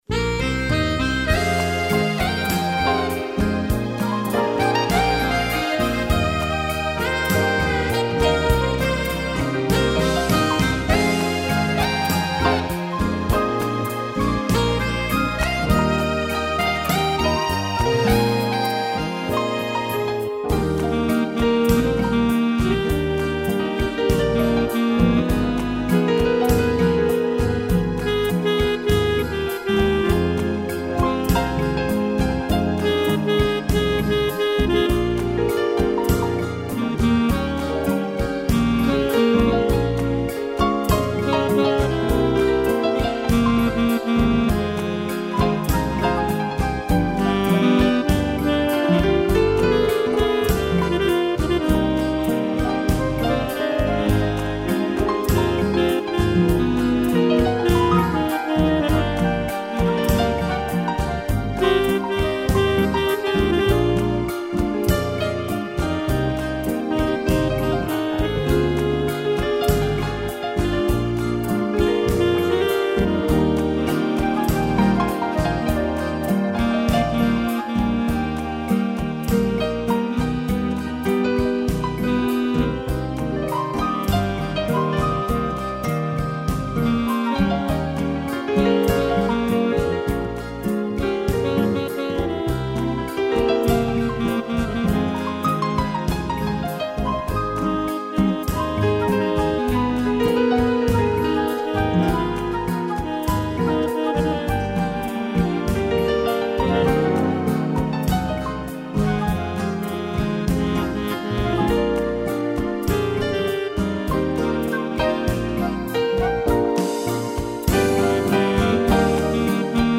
piano e flauta
(instrumental)